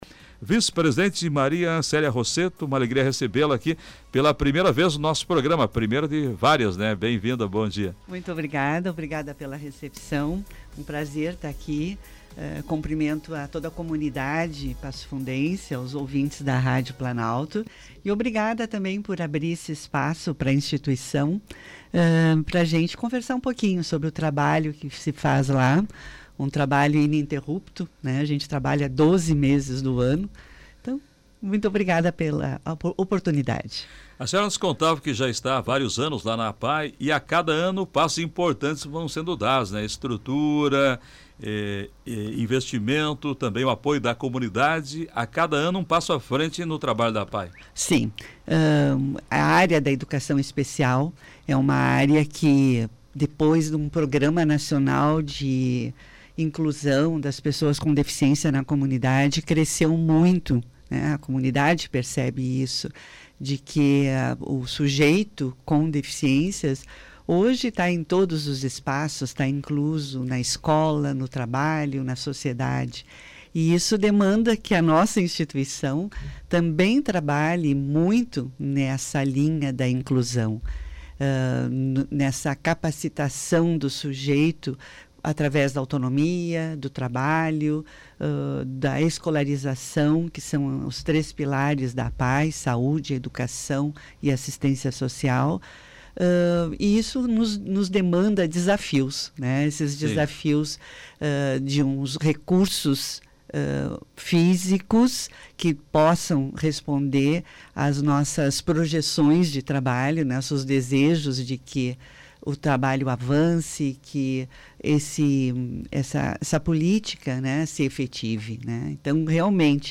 Entrevista: APAE vive importante período de crescimento, sempre ao lado da comunidade
ENTREVISTA-APAE-04-02.mp3